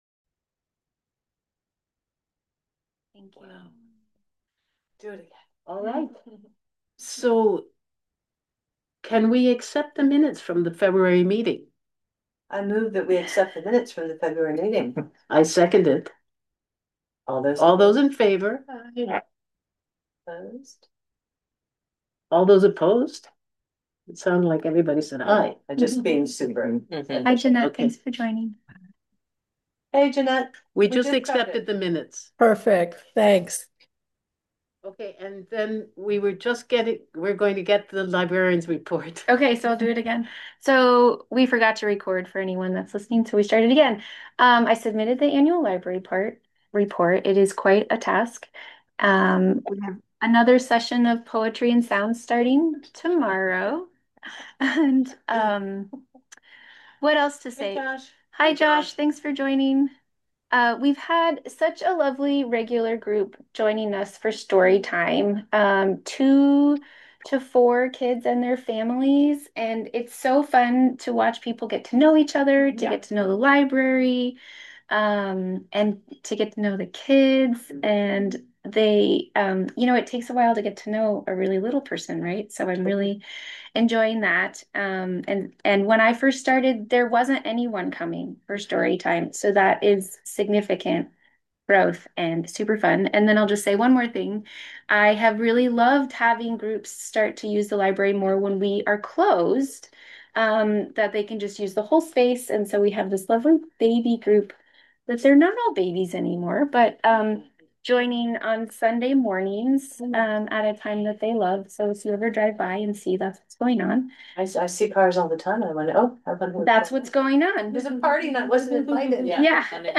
Trustees In Person & Online Meeting